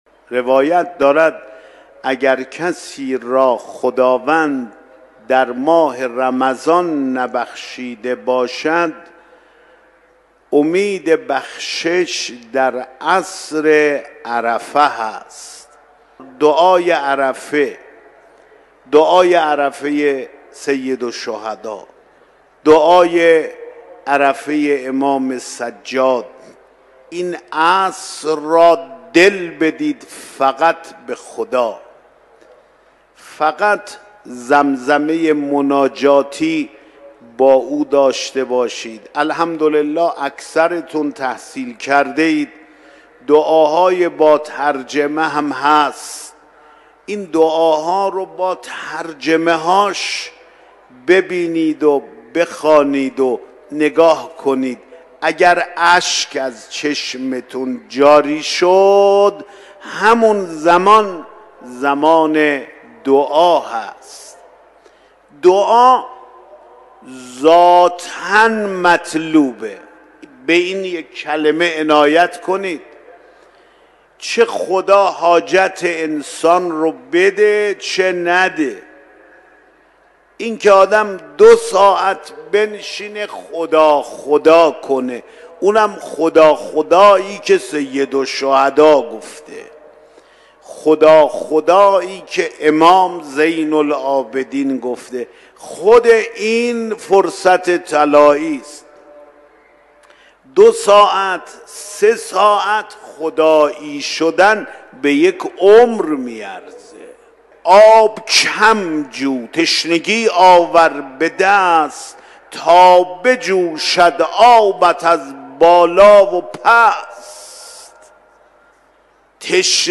فرازی از سخنرانی آیت الله سید احمد خاتمی در حرم مطهر رضوی